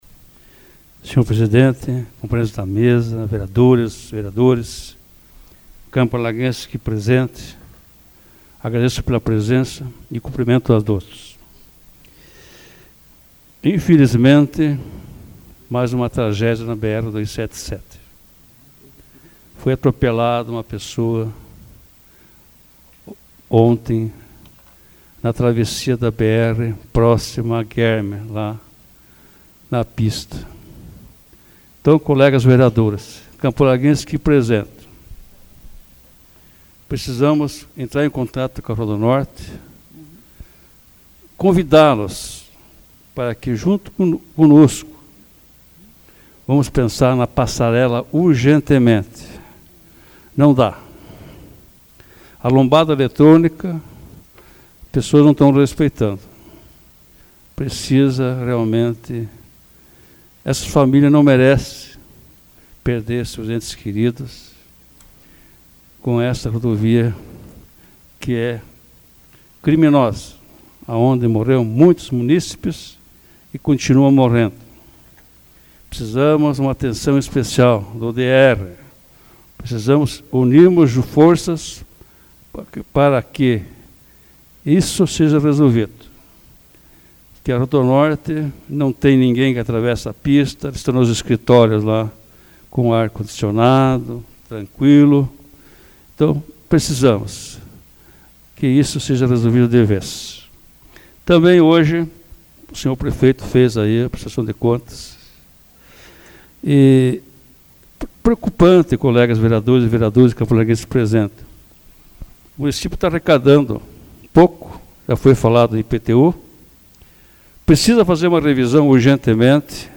Explicação pessoal AVULSO 25/02/2014 Darci Andreassa